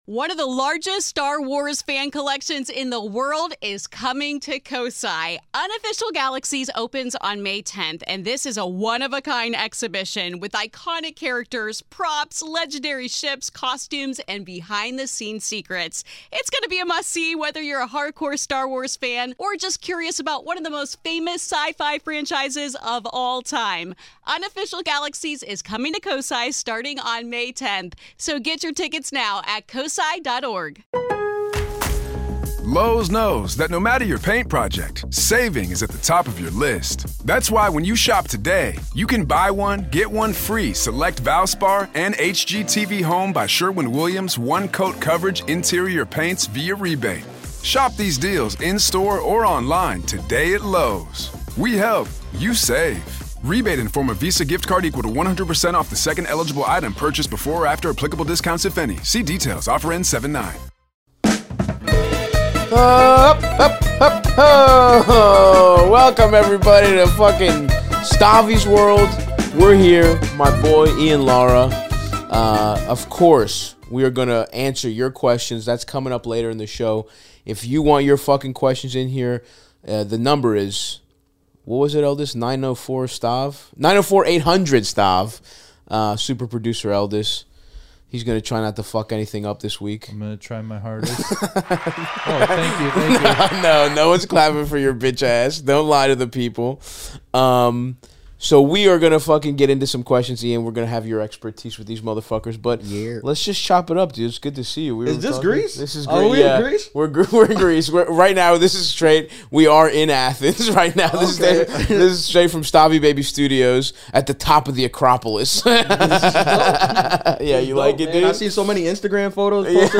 Comedian Ian Lara joins the podcast to talk about immigrant family vacations, what fame means for him in the DR versus the US, and being driven to circumcision out of self-consciousness in the locker room. Ian and Stav help callers including a guy whose buddy is being kept from a bros’ trip by his girlfriend, and a woman grappling with the ethics of being a landlord.